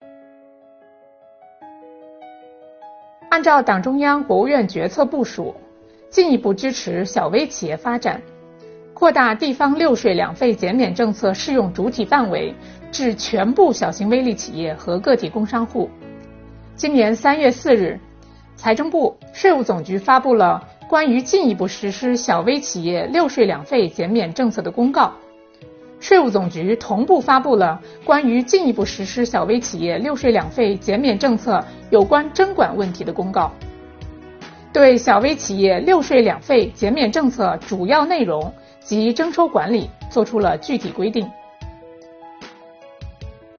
首期课程由财产和行为税司副司长刘宜担任主讲人，对“六税两费”减免政策的基本内容、系统填报、注意事项等方面进行详细讲解。